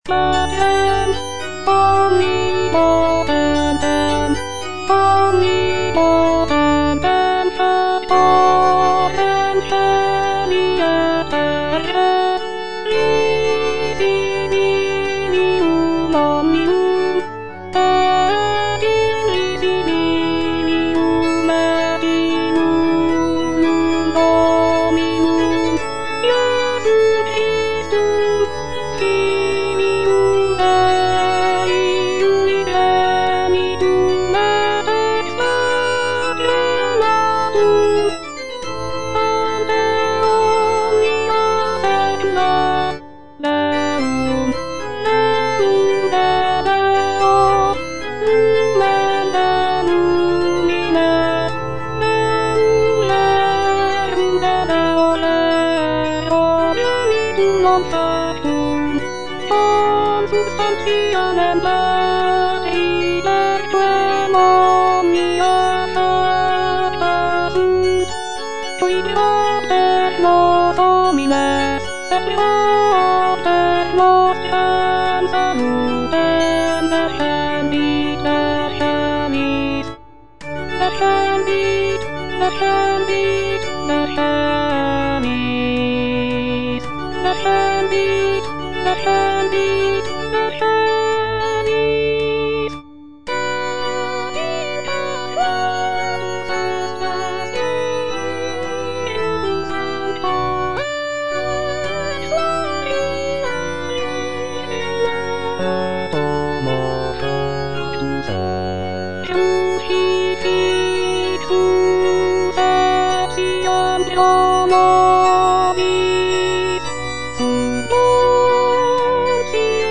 W.A. MOZART - MISSA BREVIS KV194 Credo - Alto (Voice with metronome) Ads stop: auto-stop